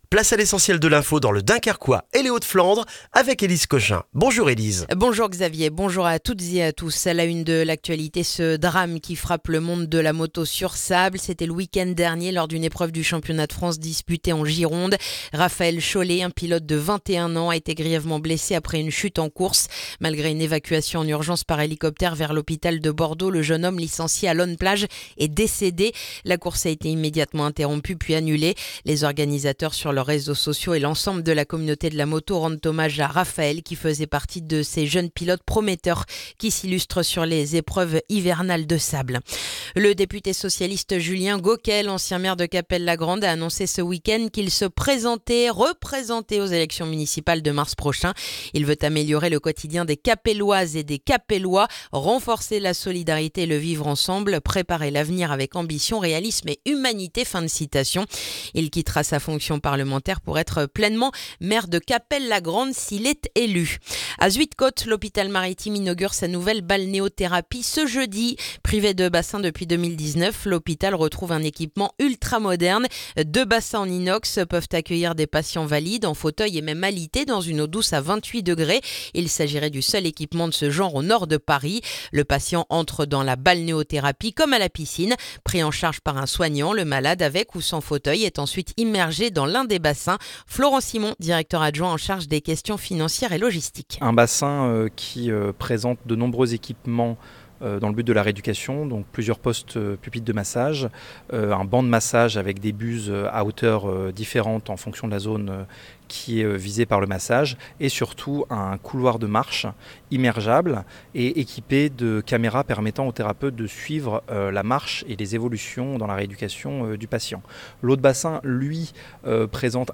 Le journal du mardi 13 janvier dans le dunkerquois